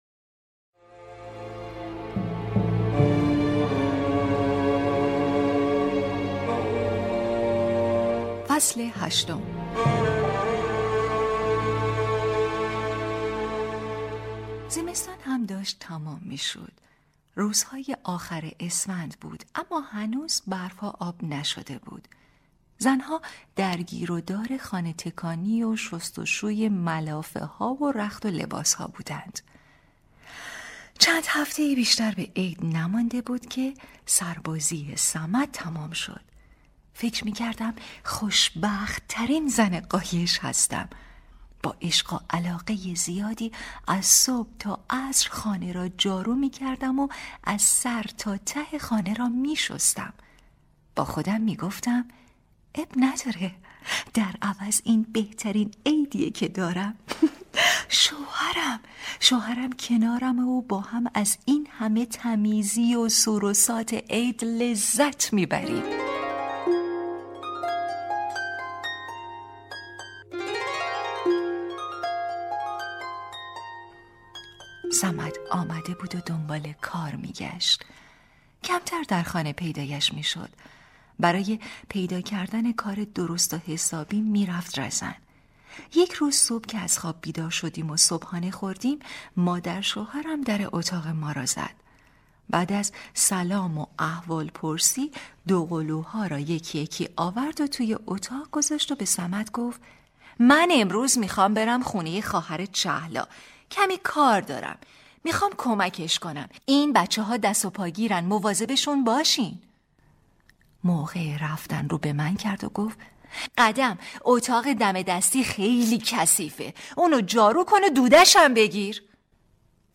کتاب صوتی | دختر شینا (06)
# روایتگری